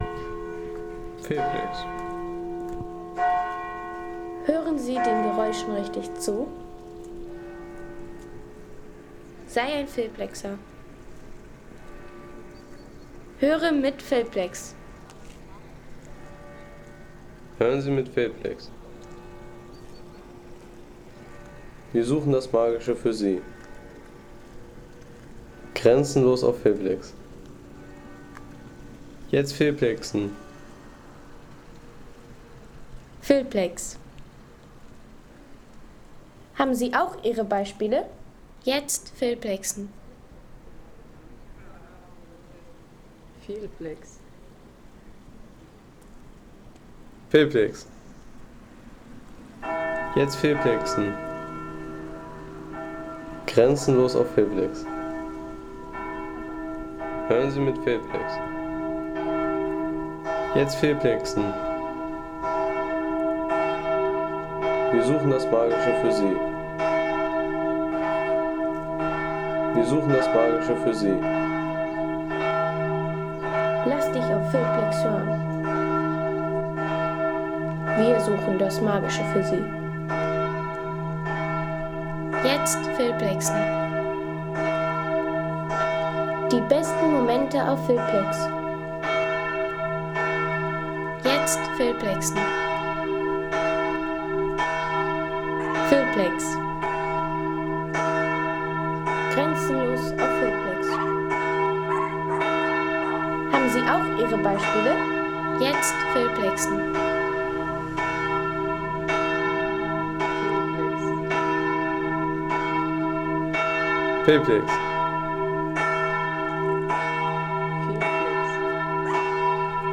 Vierzehnheiligen: Glockenklang der Basilika | Feelplex
Vierzehnheiligen: Feierliches Geläut eines Barock-Juwels
Historische Basilika bei Bad Staffelstein, bekannt durch die Überlieferung eines Heilungswunders. Die Aufnahme betont die feierlichen Glockentöne, perfekt für Film, Podcast und Klangpostkarten.